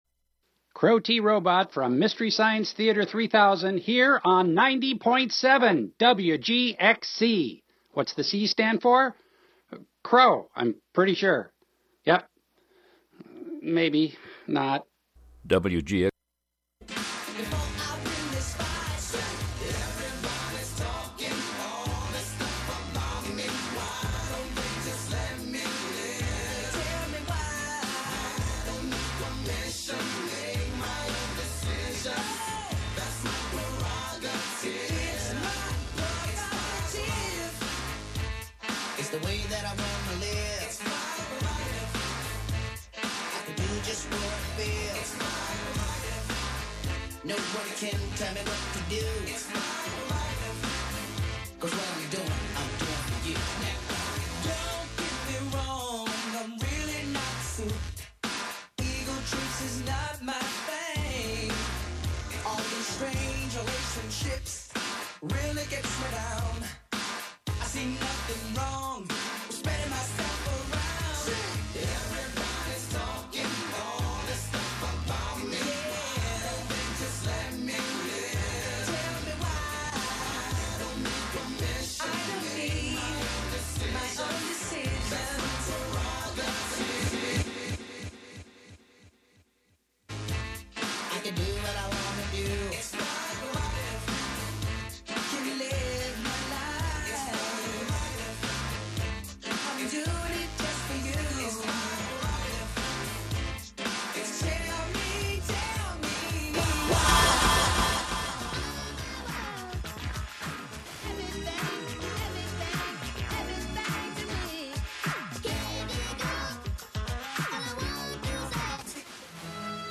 11am Live from Brooklyn, New York
instant techno